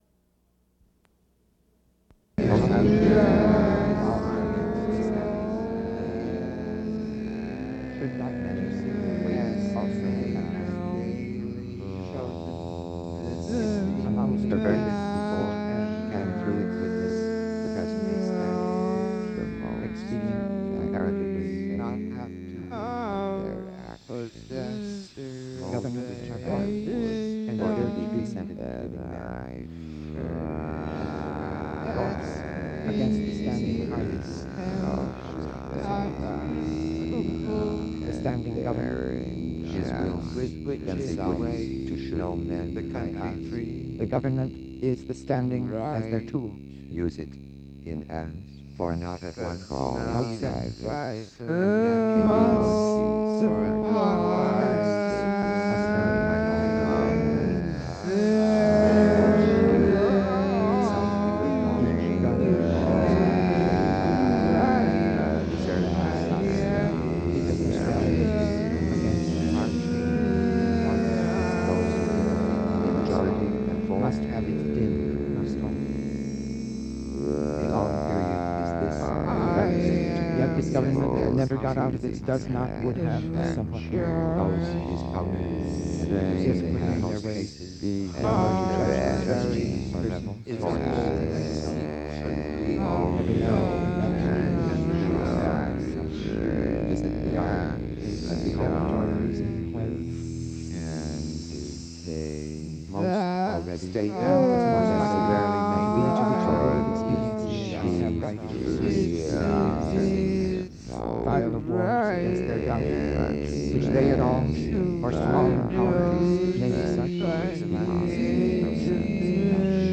The program runs three hours in its entirety, mostly taken up by the working process required to mix (and remix) opera snippets by turntable engineers working with recordings brought together in the studios of WKCR 89.9-FM, the radio station at Columbia University.